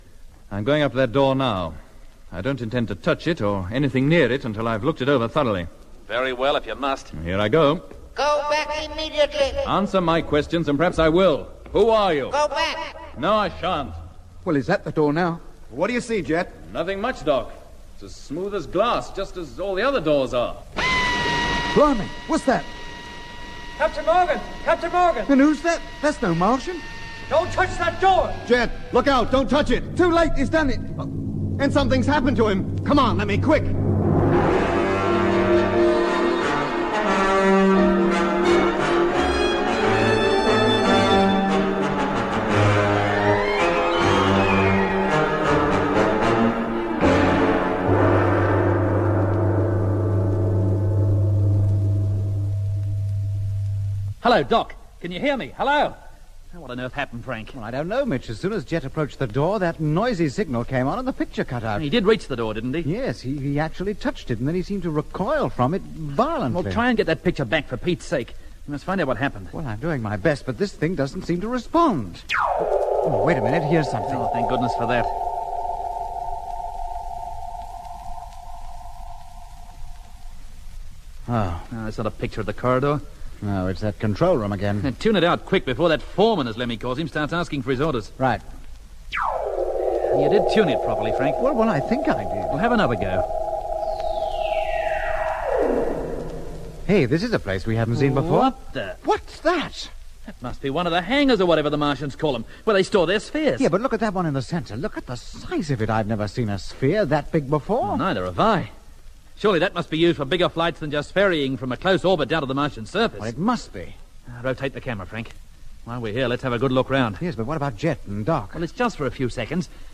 Wanneer Jet/Jeff naar de deur gaat, volgt er een klap.